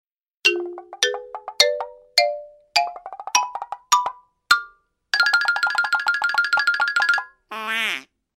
Звуки мультяшных шагов
Подкрадывается из мультика